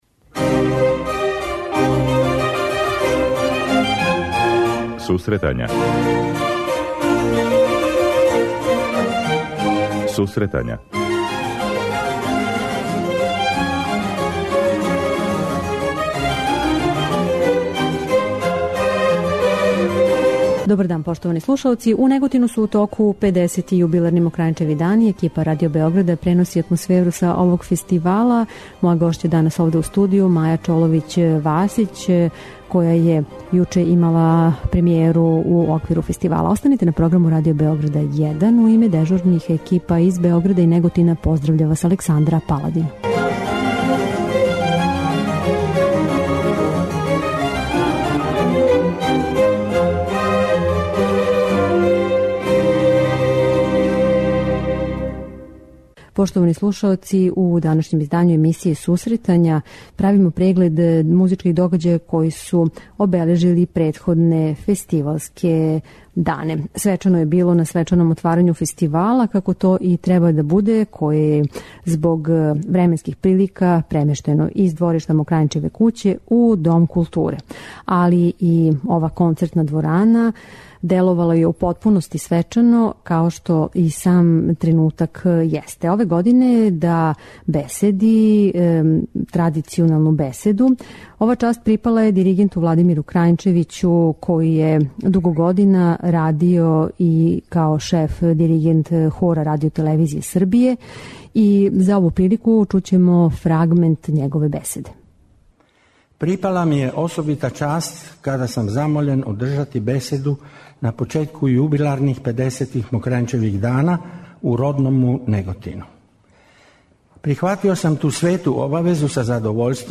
Такође, чућемо и обраћање Министра за културу и информисање Ивана Тасовца и амбасадора Мајкла Девенпорта, шефа делегације Европске уније у Србији.